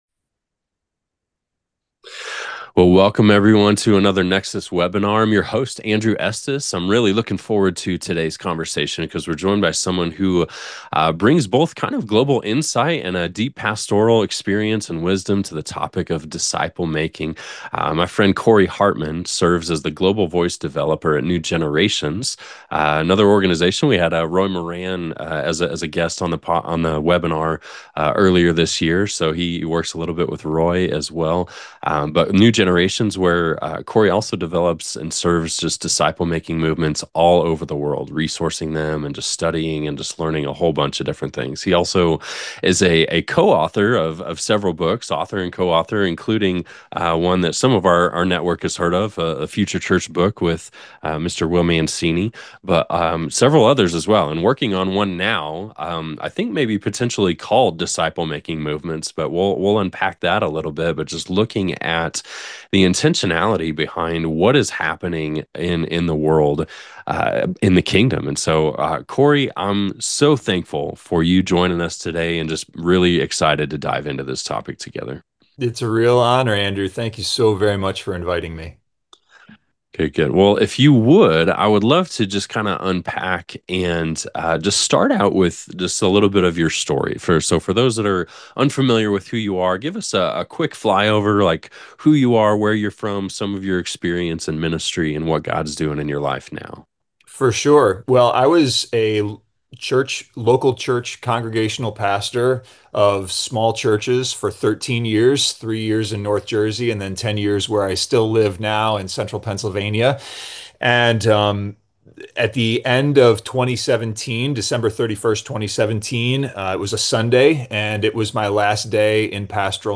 for a powerful conversation on disciple-making movements.